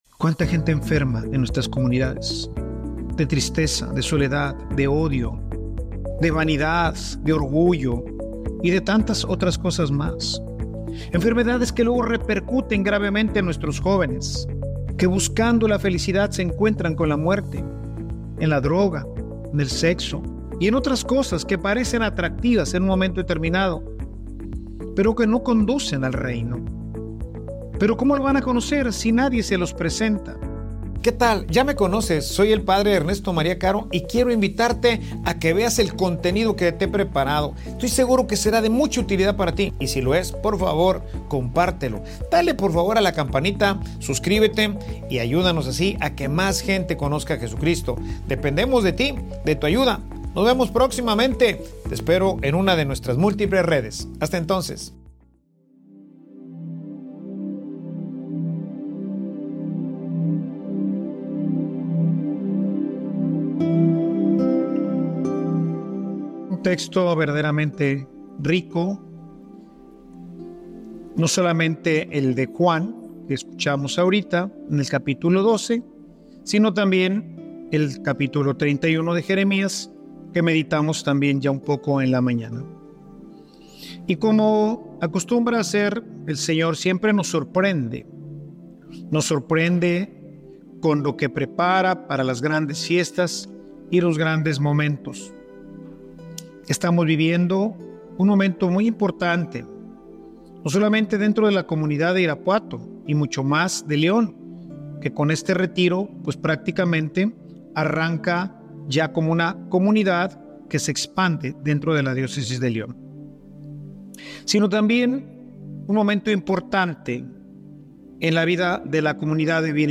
Homilia_Urgen_camilleros_que_los_lleven_a_dios.mp3